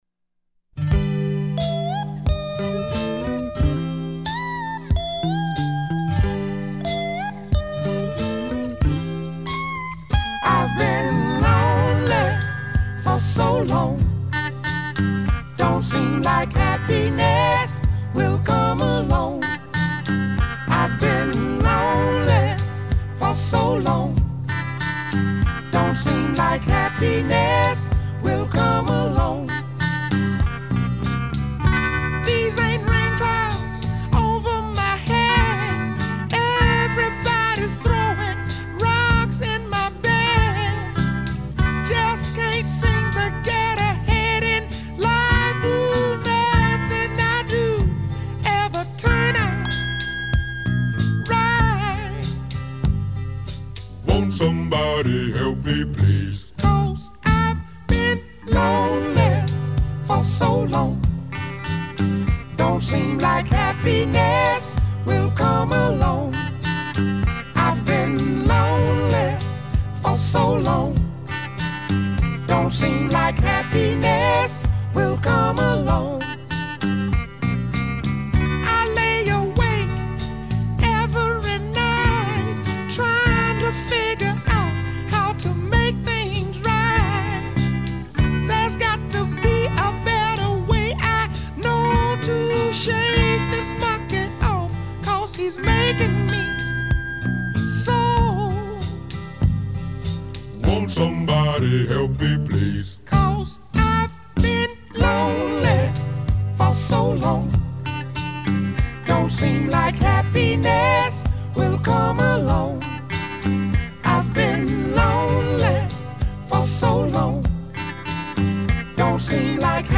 Soul singer